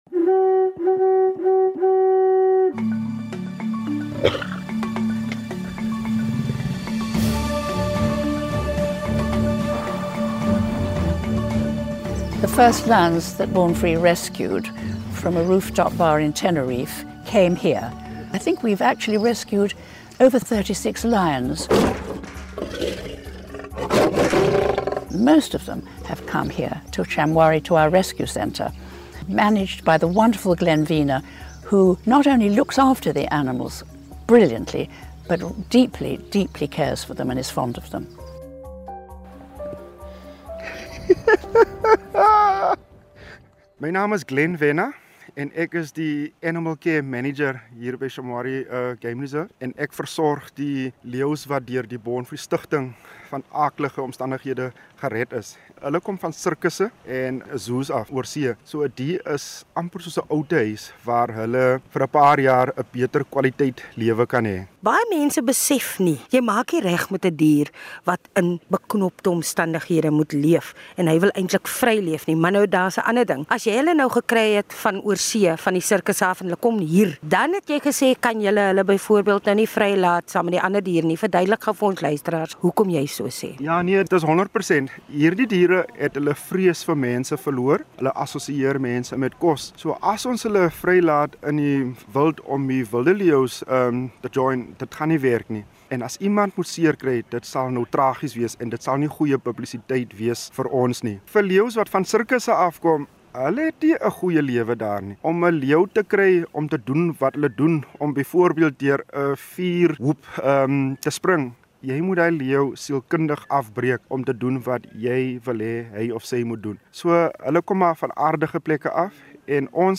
By die Born Free-bewaringsorganisasie op die Shamwari-wildreservaat in die Oos-Kaap